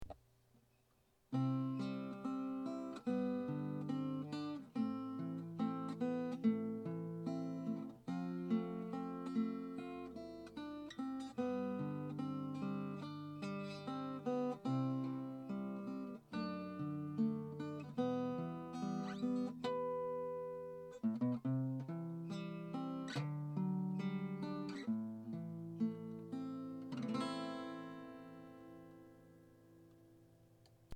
まずはマイクとエレアコの音
サウンドホール前の机において
ＰＣのマイク端子からの録音
MIC.mp3